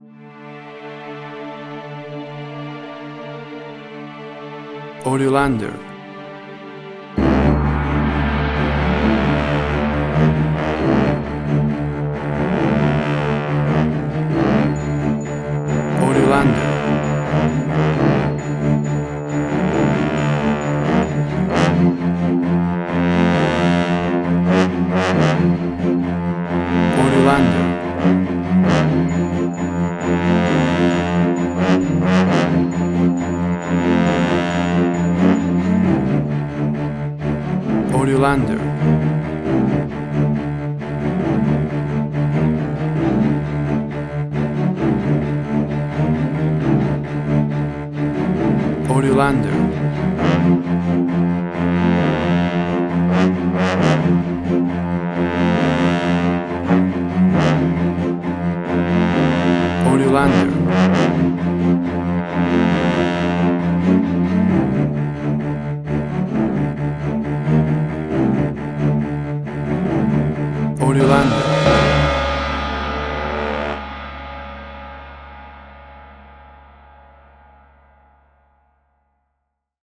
WAV Sample Rate: 24-Bit stereo, 48.0 kHz
Tempo (BPM): 135